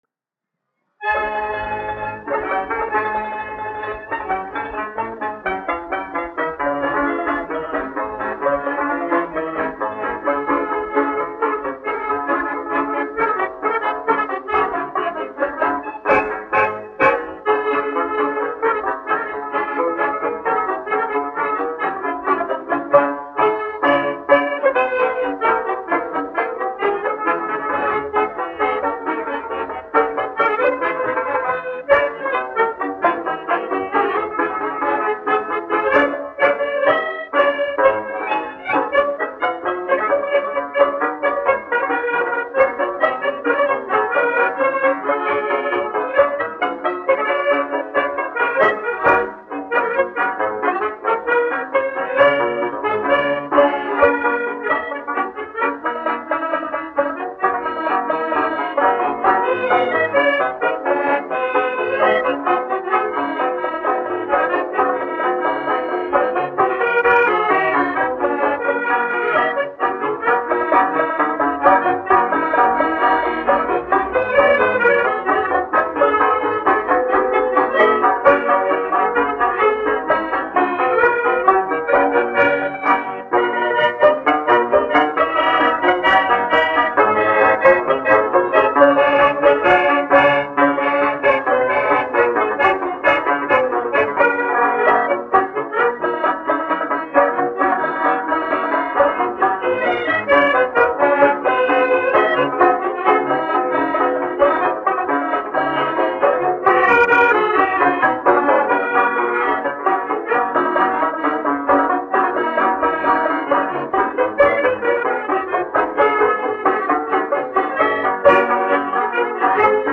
1 skpl. : analogs, 78 apgr/min, mono ; 25 cm
Sarīkojumu dejas
Populārā instrumentālā mūzika
Skaņuplate